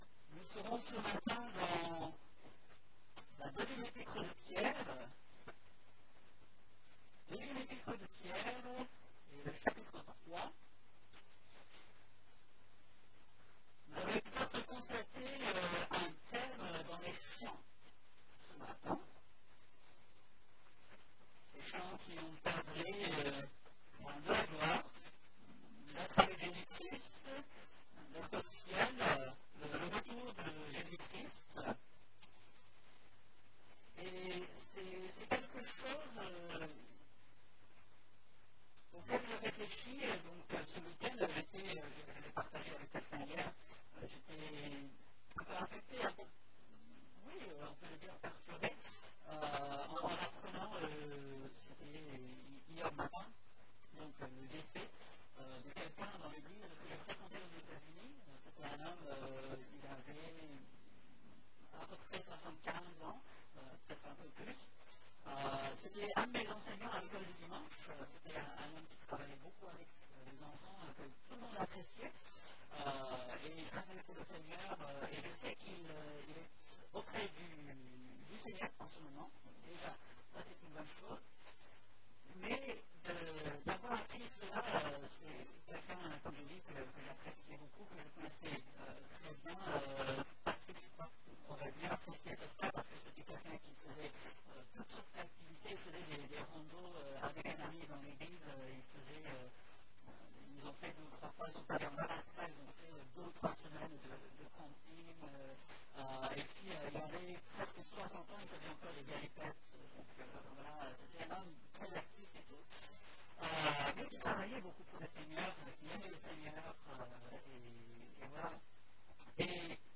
Ici nous vous proposons l'écoute des prédications qui sont apportées le dimanche matin.